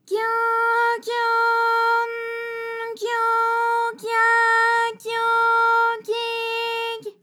ALYS-DB-001-JPN - First Japanese UTAU vocal library of ALYS.
gyo_gyo_n_gyo_gya_gyo_gyi_gy.wav